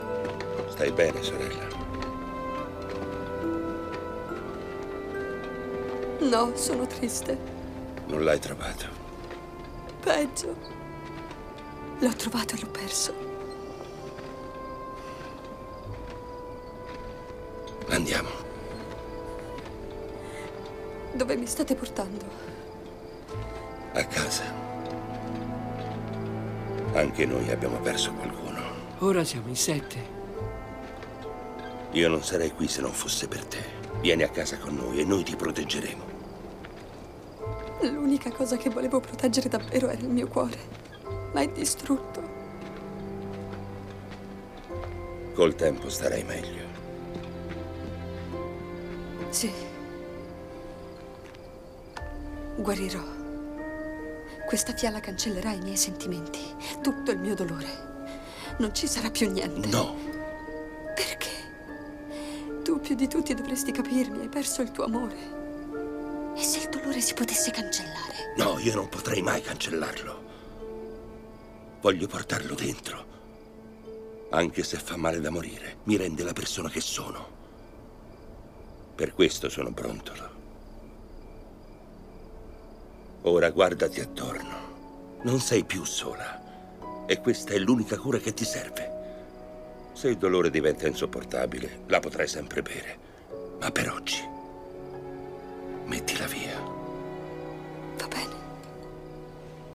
nel telefilm "Once Upon a Time - C'era una volta", in cui doppia Lee Arenberg.